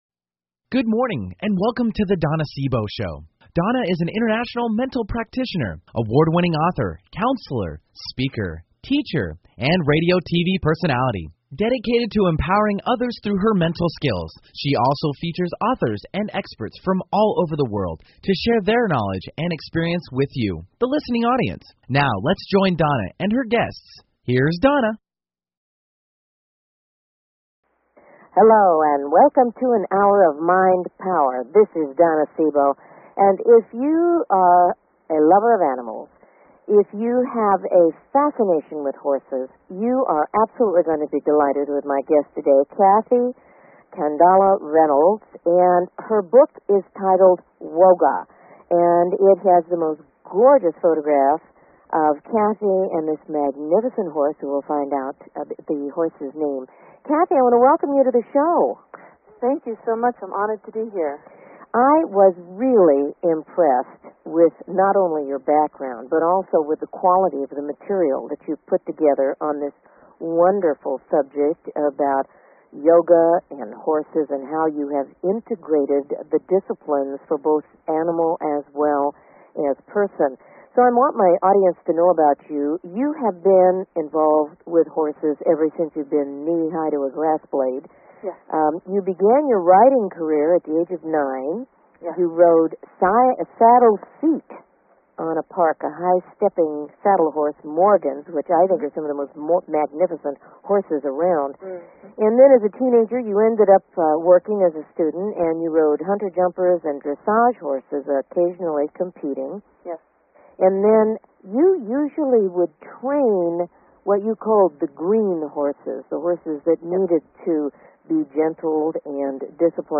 Talk Show Episode
Some surprises in store with this interview.